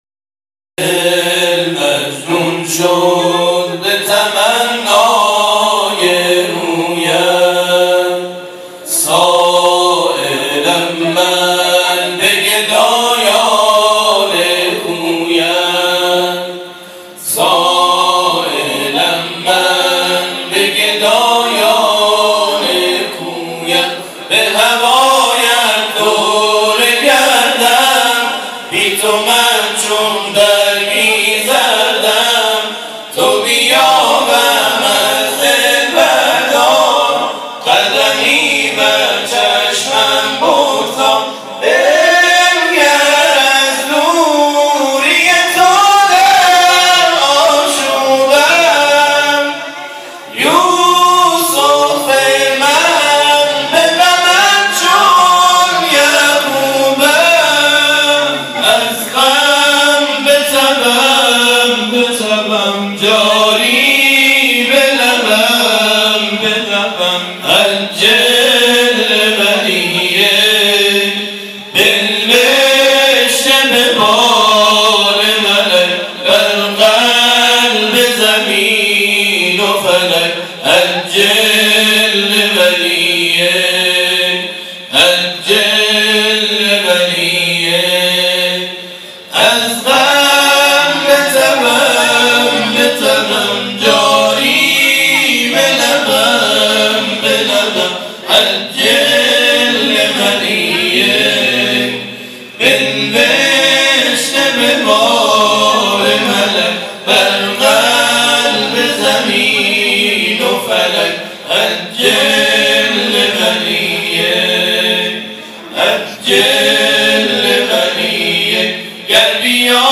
گروه فعالیت‌های قرآنی: نوجوانان و جوانان شهر ساوه شب گذشته با حضور در مسجد صاحب الزمان(عج) ساوه، محفل باشکوه انس با قرآن کریم را برپا کردند.
سپس گروه تواشیح بقیه الله(عج) به اجرای دو اثر خود پرداخت.
در ادامه صوت‌های این مراسم ارائه می‌شود.